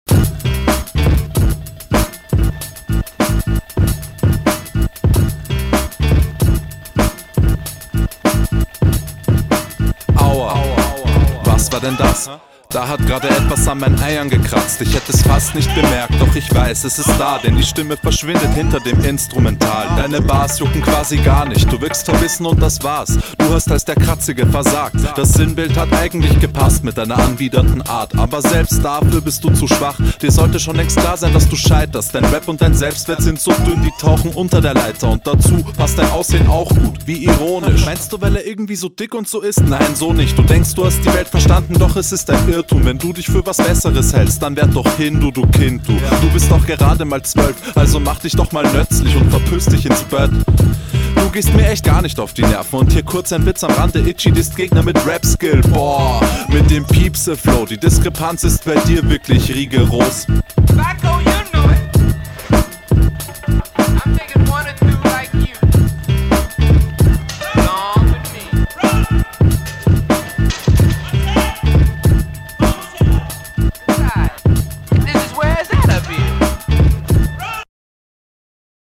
Stranger Beat, eingstieg sehr chillig, du rappst iwie mit mehr druck als früher, sehr cool, …